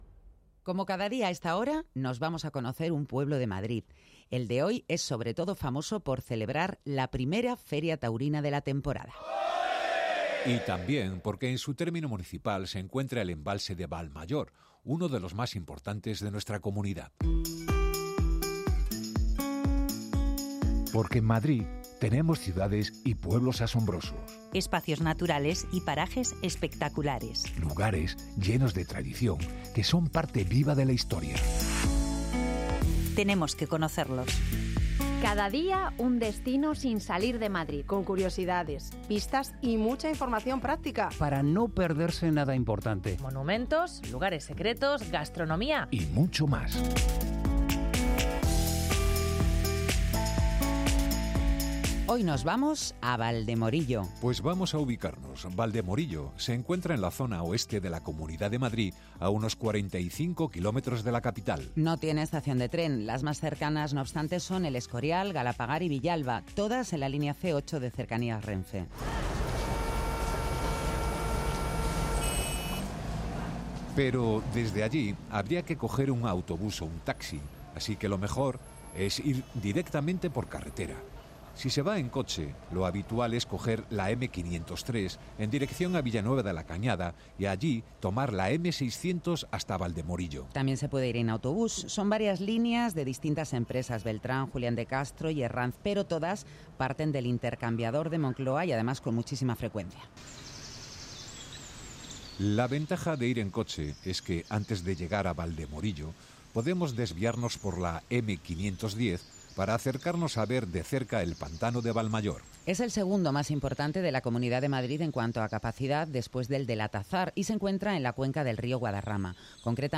Dos periodistas